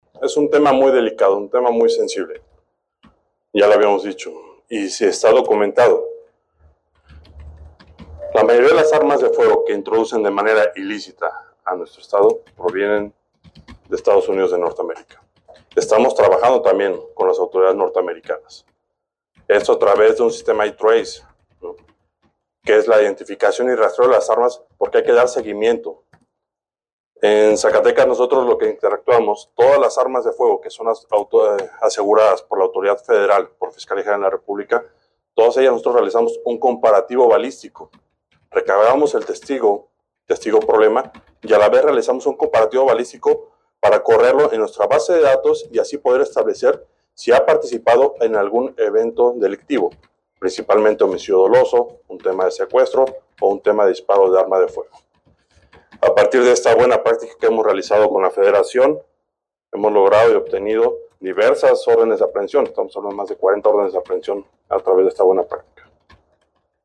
AUDIO: CRISTIAN PAUL CAMACHO, FISCAL GENERAL DEL ESTADO (FGE) DE ZACATECAS